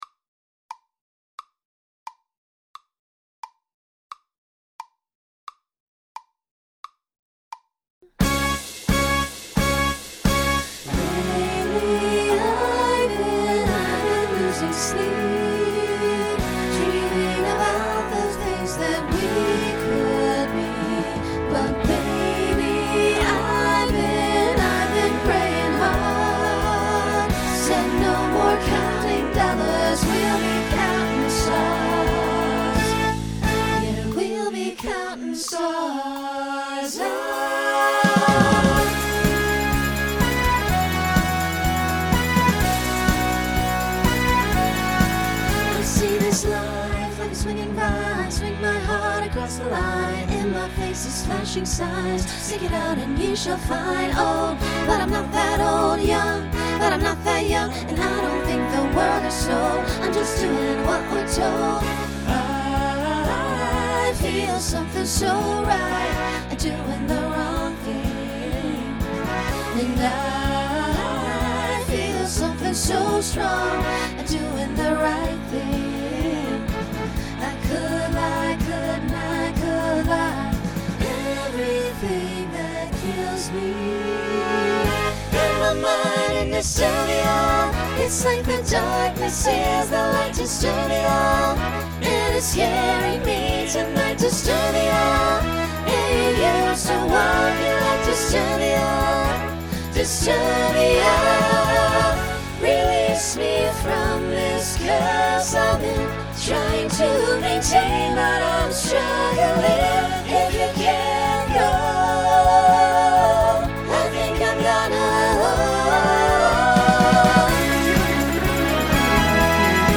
Genre Pop/Dance , Rock
Story/Theme Voicing SATB